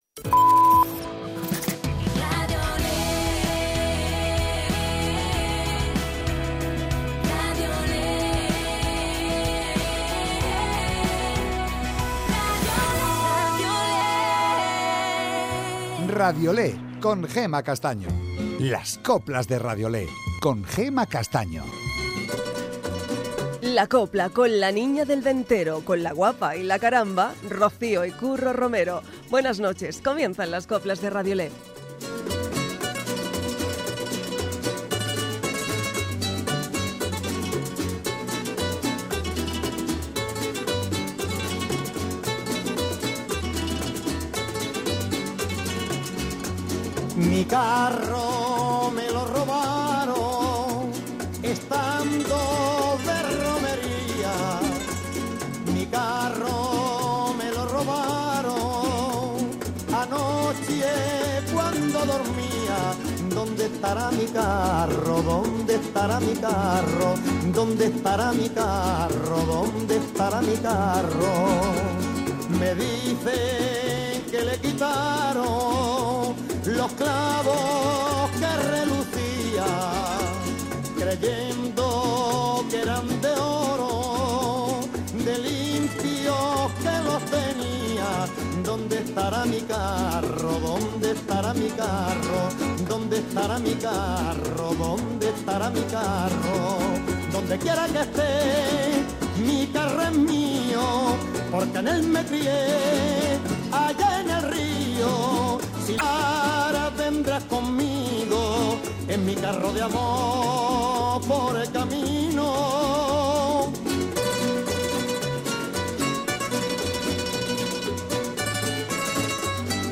Las mejoras coplas de hoy y de siempre, en Radiolé